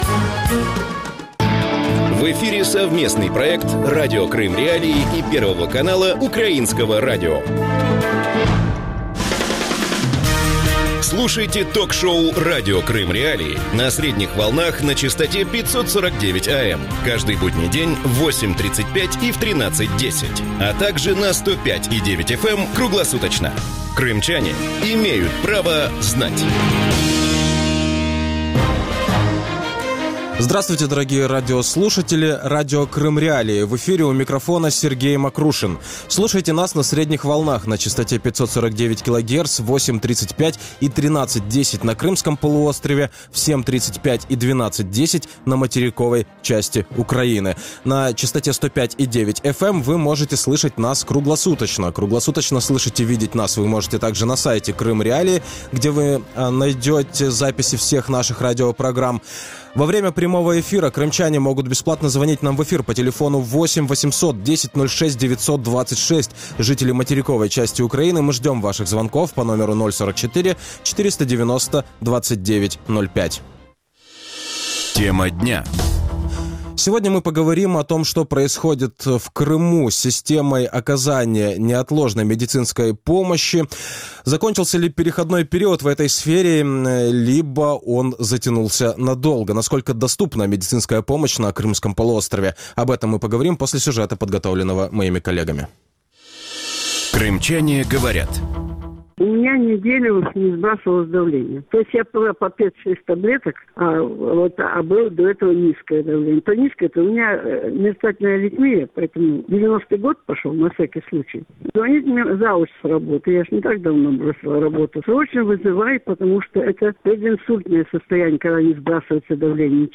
Что происходит с системой оказания неотложной медицинской помощи в аннексированном Крыму? Закончился ли переходной период в сфере крымского здравоохранения? Насколько доступна медицинская помощь на полуострове? Гости эфира